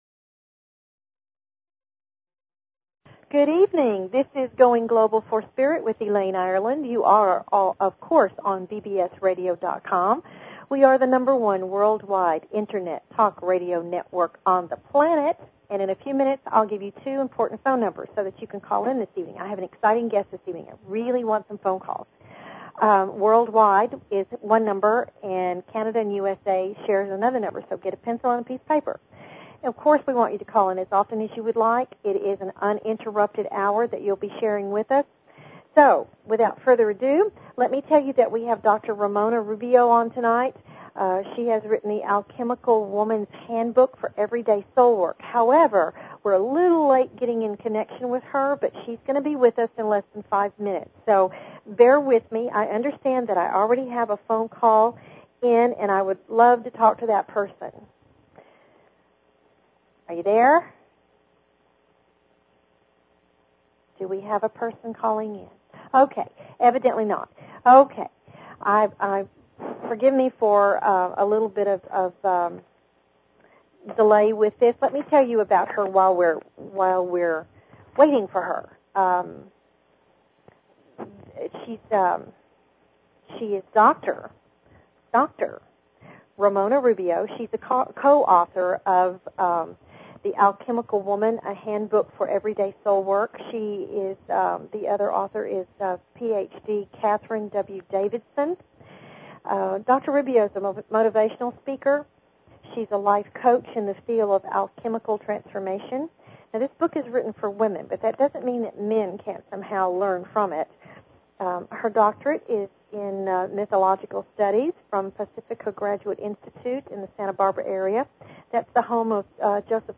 Courtesy of BBS Radio
A variety of guests will be here to teach and share their wonders with you. They invite you to call in with your questions and comments about everything metaphysical and spiritual!"